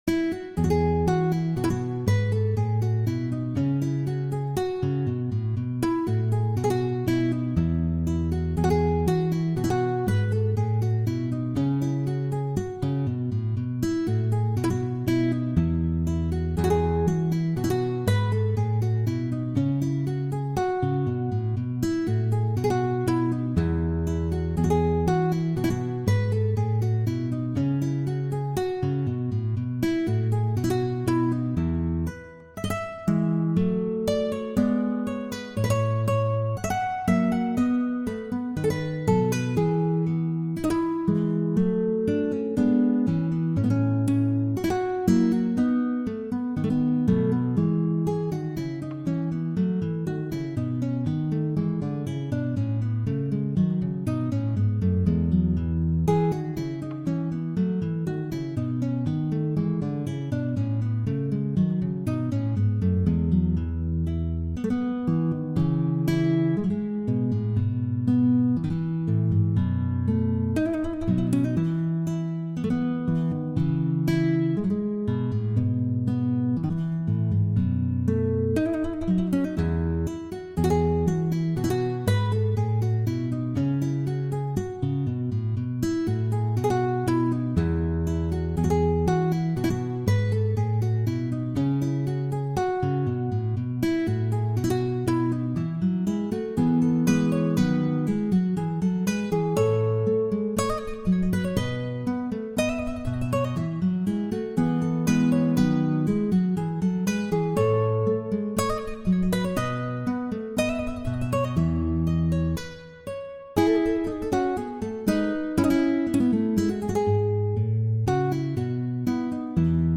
La Buisson (chaconne de la suite n° 2).pdf